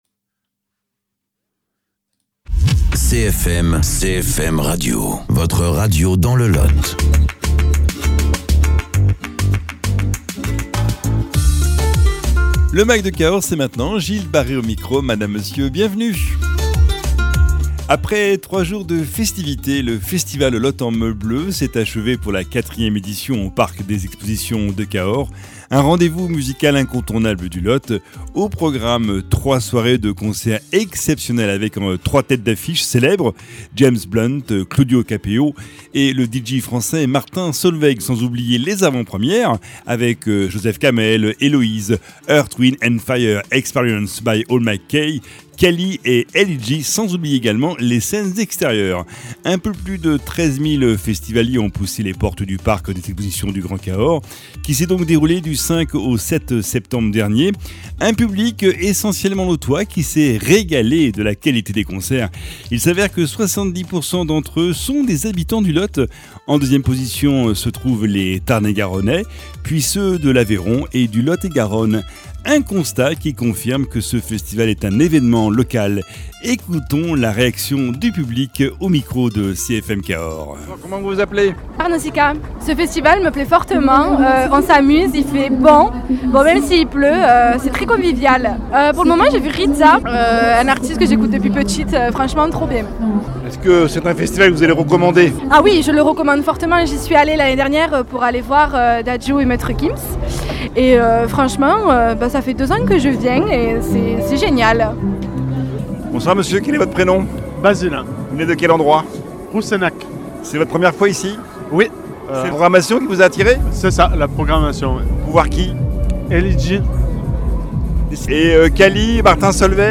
La 4e édition du festival Le Lot en Meule Bleue s’est terminée samedi 7 septembre.. CFM Cahors a recueilli les réactions des festivaliers, partenaires et bénévoles au cours de cet évènement musical au parc des expositions de Cahors...